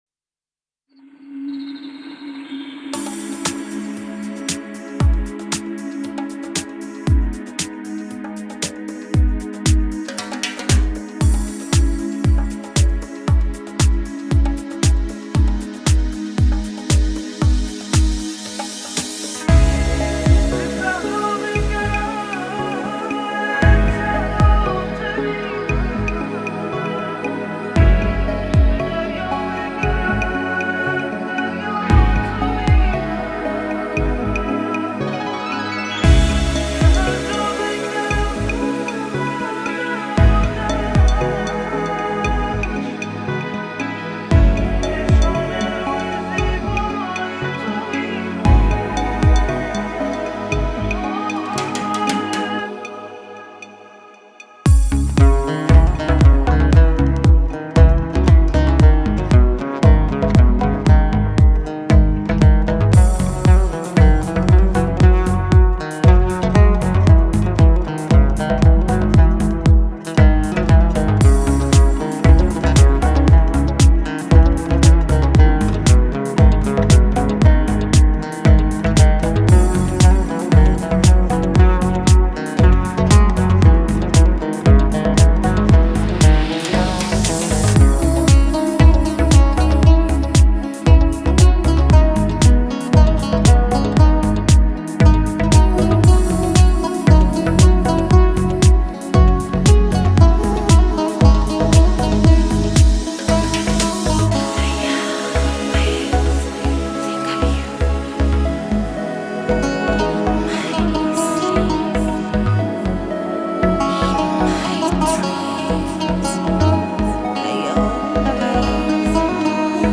это трек в жанре прогрессивный хаус